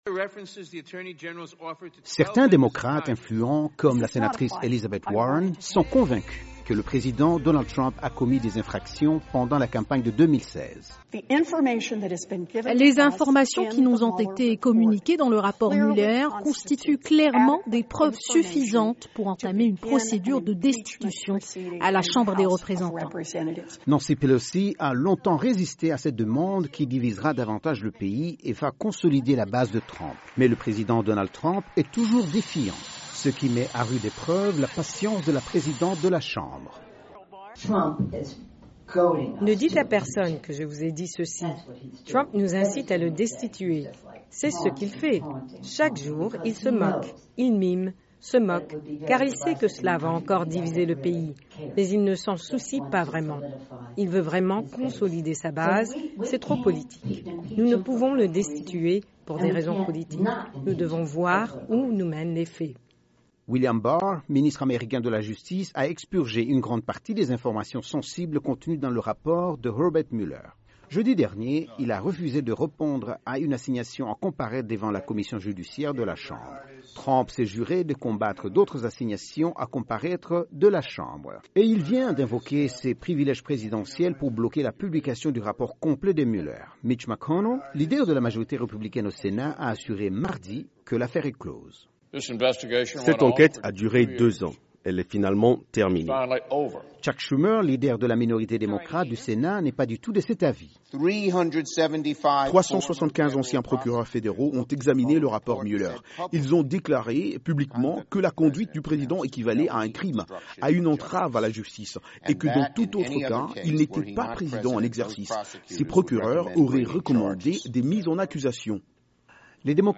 Le chef de la majorité républicaine du Sénat, Mitch McConnell a déclaré que le rapport de Mueller ne donne aucune preuve de connivence de l’équipe de campagne de Trump avec la Russie. Par contre la démocrate Nancy Pelosi, présidente de la chambre, estime que l'enquête est loin d'être terminée. Reportage